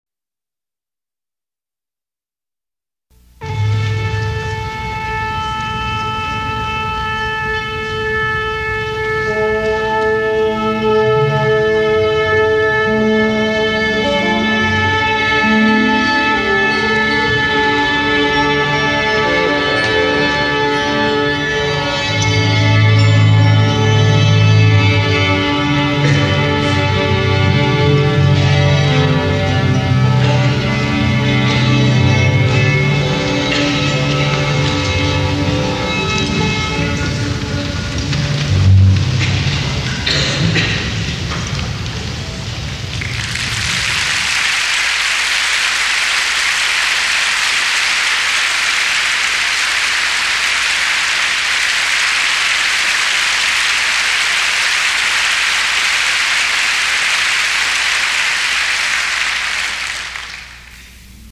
sound FX